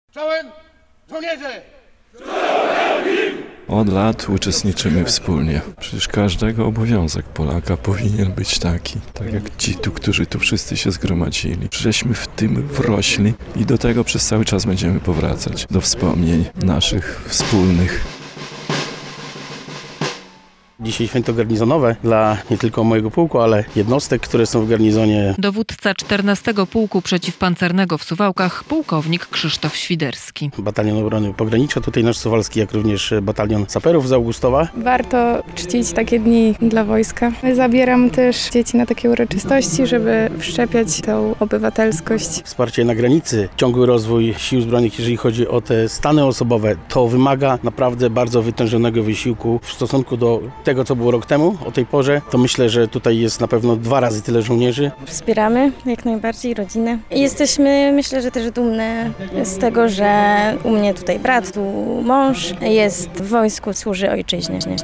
Żołnierze z Suwalszczyzny i Augustowszczyzny zebrali się na uroczystym apelu
W przeddzień (14.08) Święta Wojska Polskiego na uroczystym apelu zebrali się żołnierze z Suwalszczyzny i Augustowszczyzny.